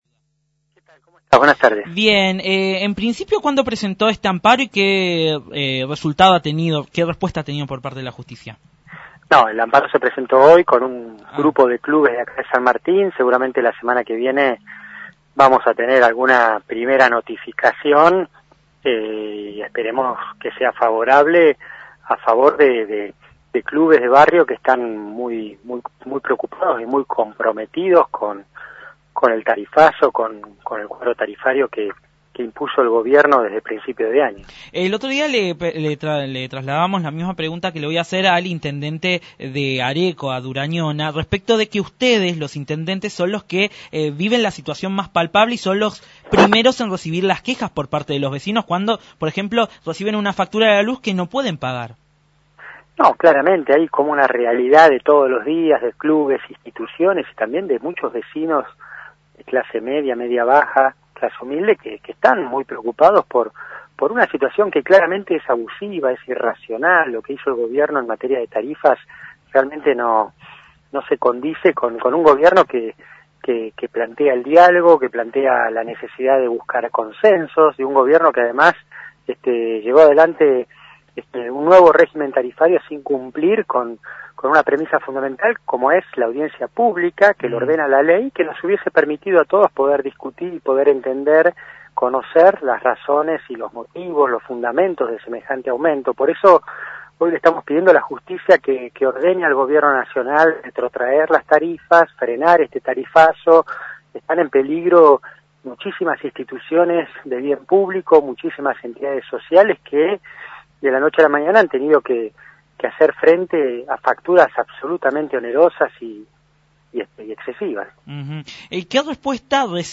Gabriel Katopodis, Intendente de San Martín, pasó por el aire de Nube Sonora para referirse a los tarifazos que tiene en vilo no solo a la sociedad sino también a los clubes de barrio que se ven cercados y bajo amenaza de cierre por los aumentos que superan el 500%.